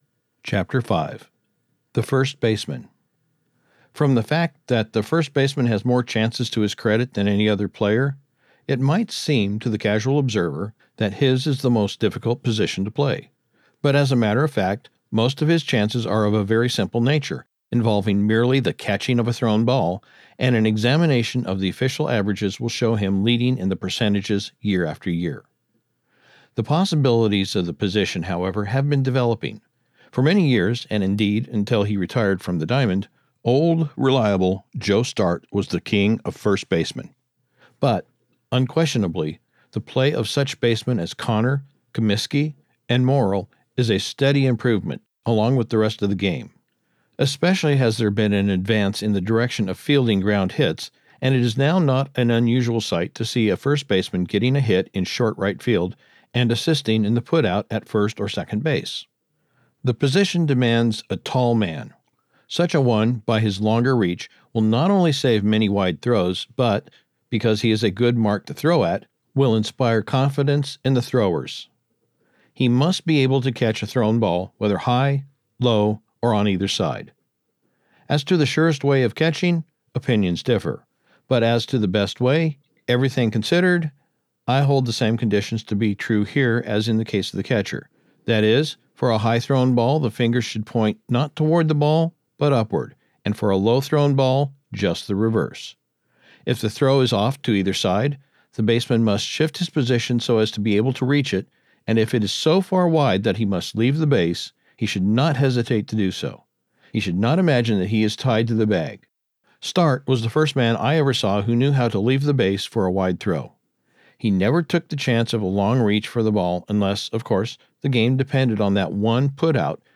Audiobook production Copyright 2025, by GreatLand Media